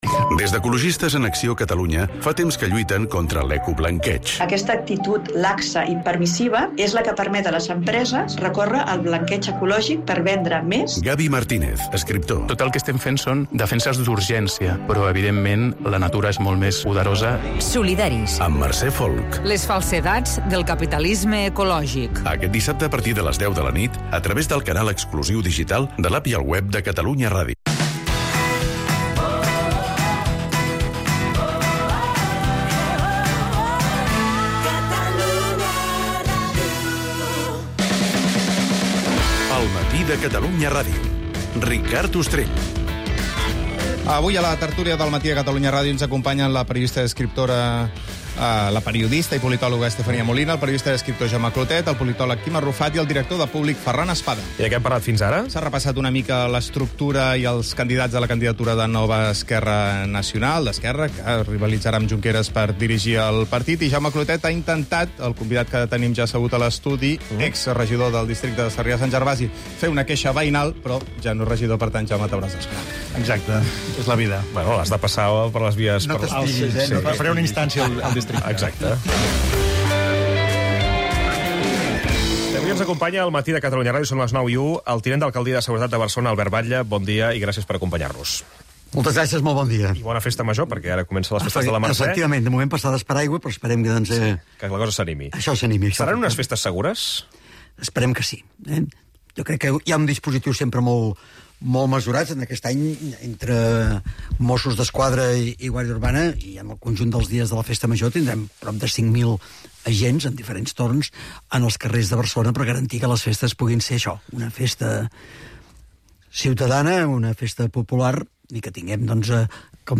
El mat, de 9 a 10 h (tertlia) - 20/09/2024